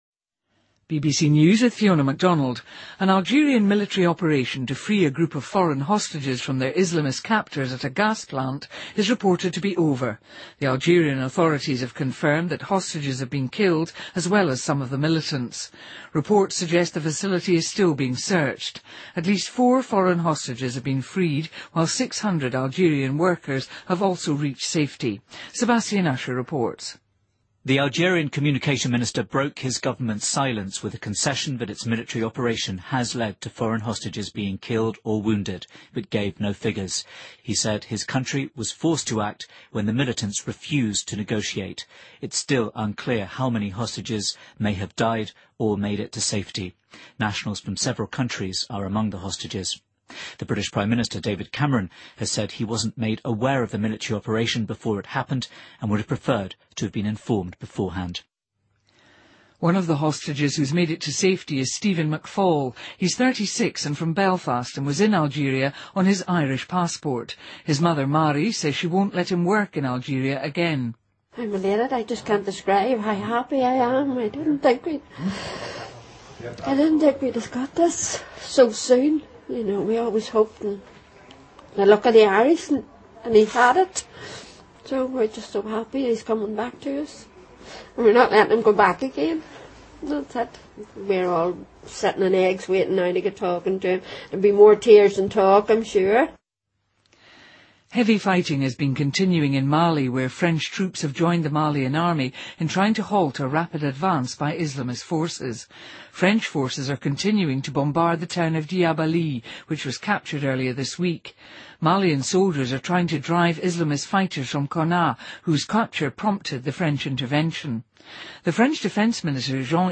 BBC news,美国20年来首次正式承认索马里政府